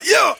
Yup.wav